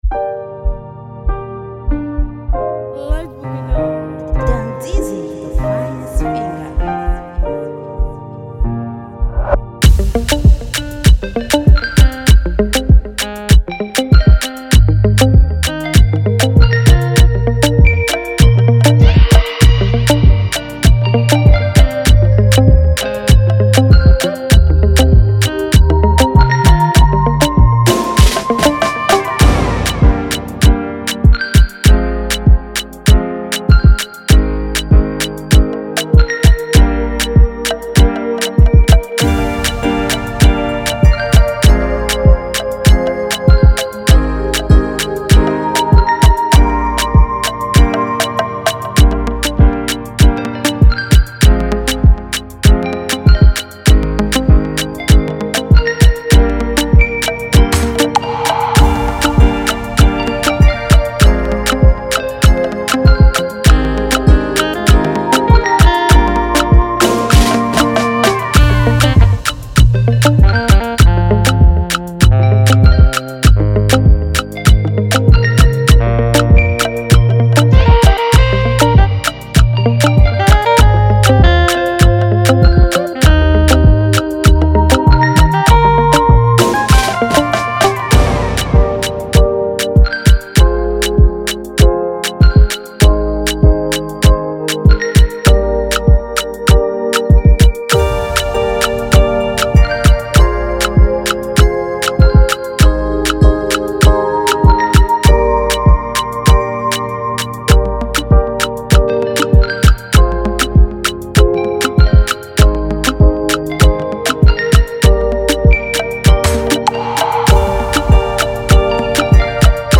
blends vibrant rhythms with a modern club vibe.